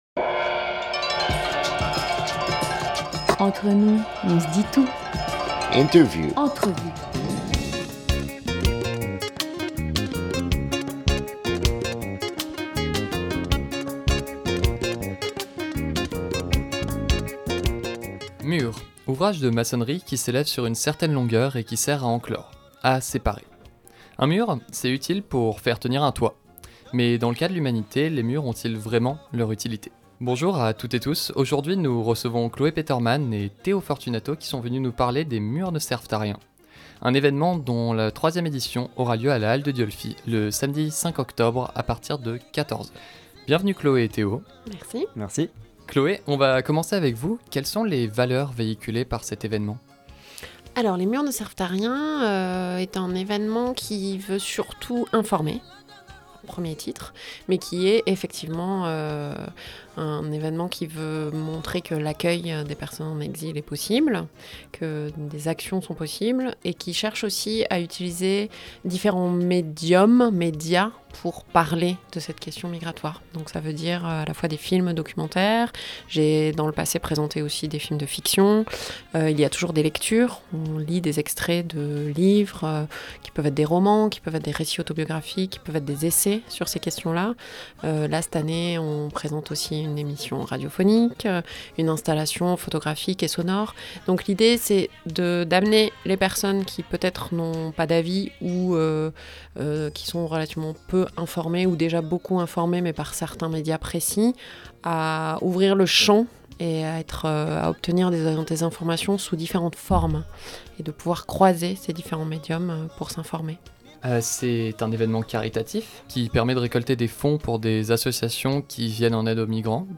7 octobre 2019 15:20 | Interview